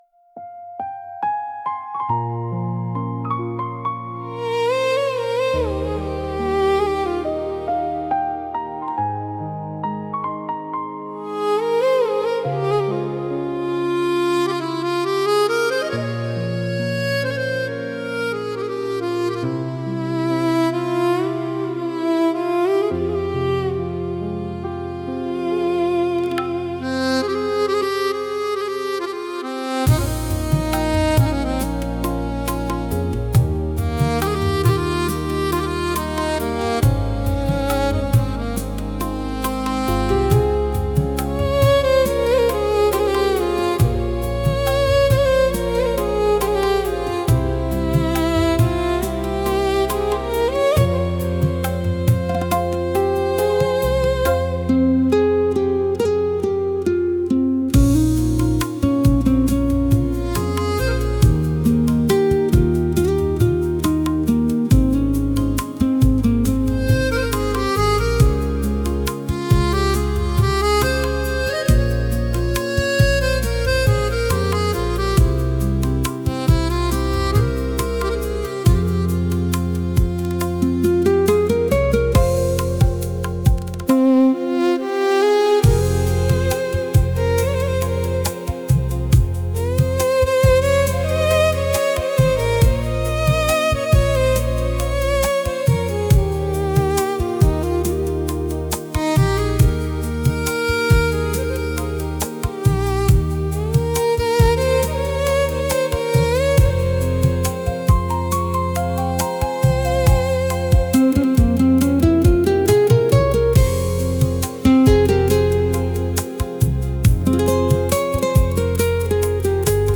बारिश की रुनझुन 📥 800+ Downloads 00:03:14